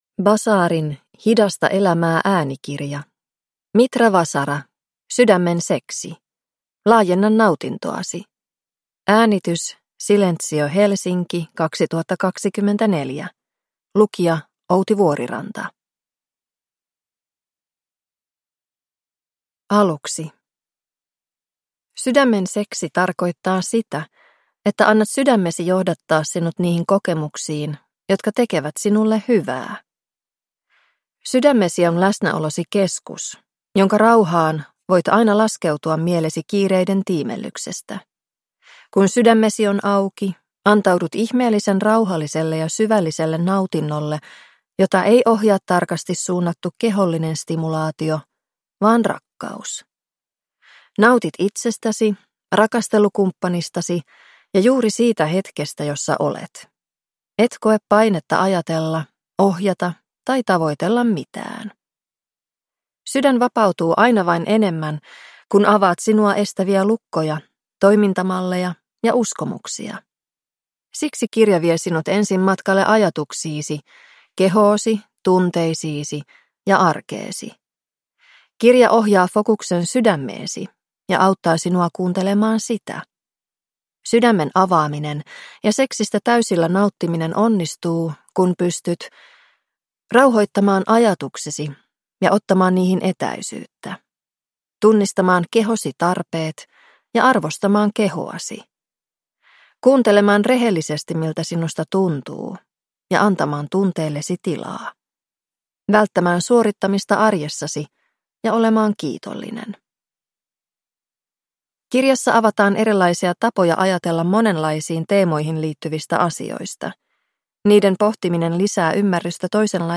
Sydämen seksi – Ljudbok